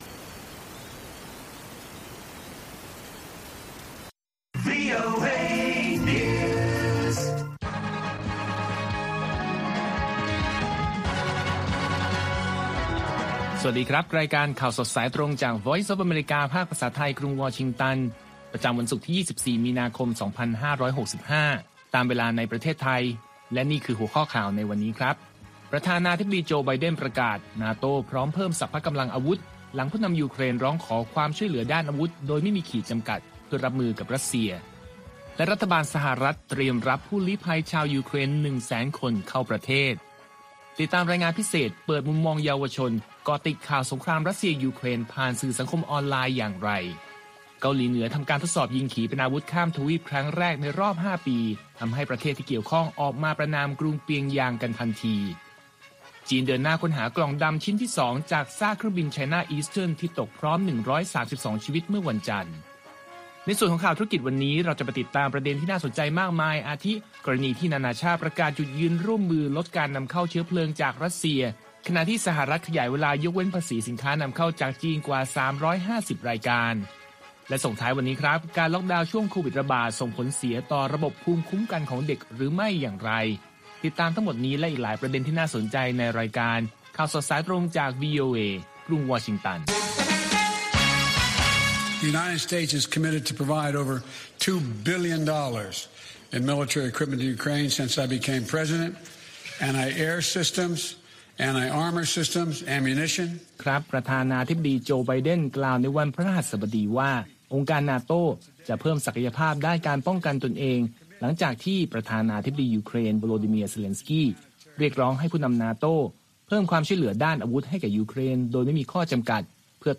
ข่าวสดสายตรงจากวีโอเอ ภาคภาษาไทย ประจำวันศุกร์ที่ 25 มีนาคม 2565 ตามเวลาประเทศไทย